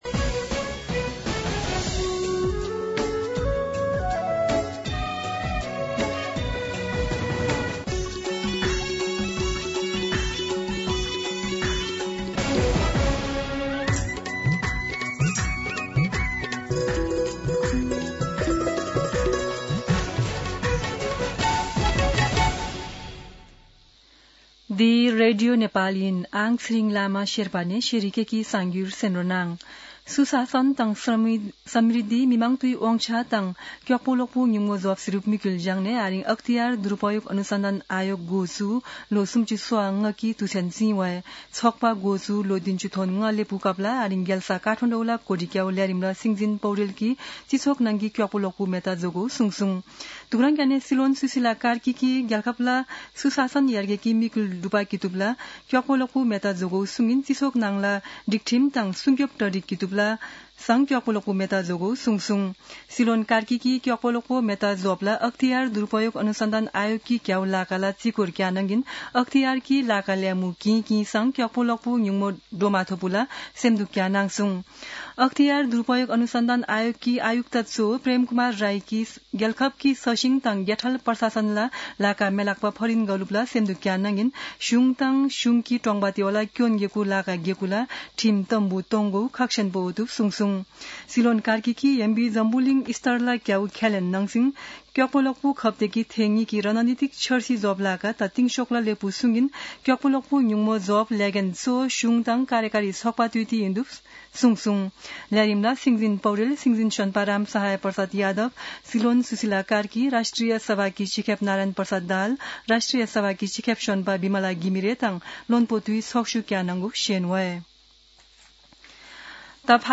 शेर्पा भाषाको समाचार : २८ माघ , २०८२
Sherpa-News-10-28.mp3